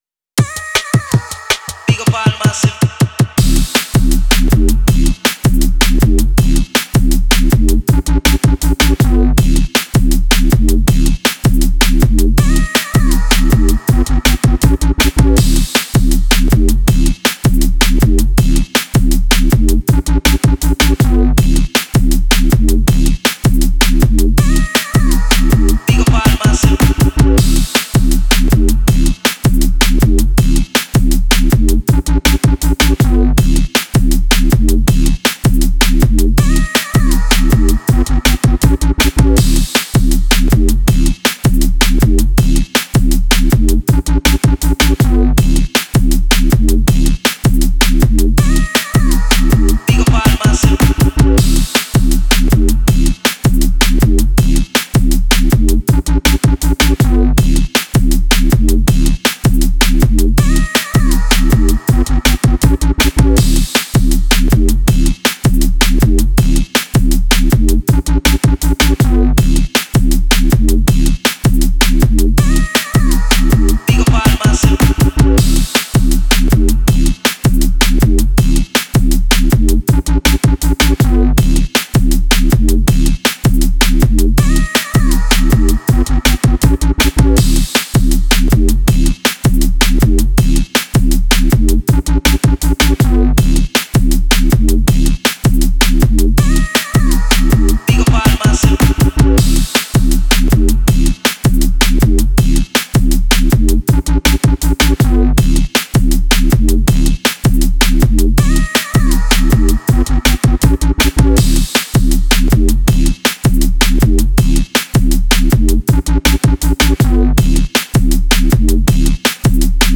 Be sure to practice across different styles as they have slightly different rhythms that will broaden your scratching techniques Speed trainer - a 4 minute track that goes from 80bpm to 180 bpm.